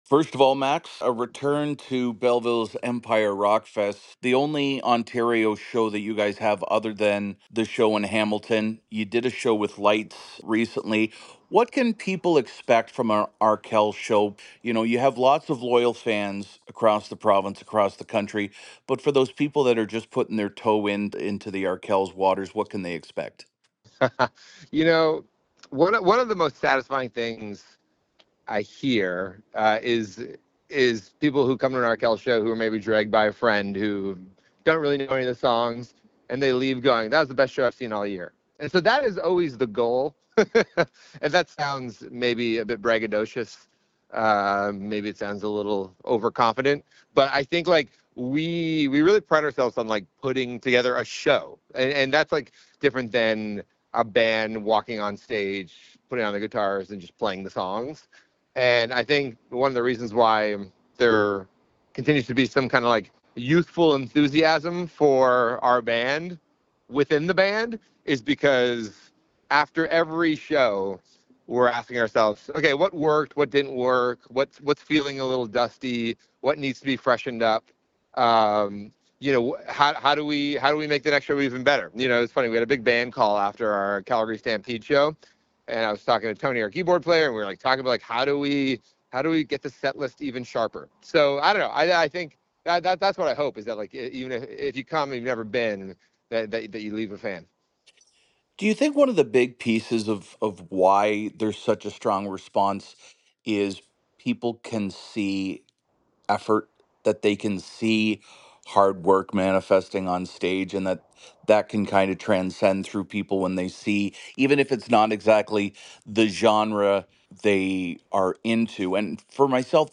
His full interview with Quinte News is below.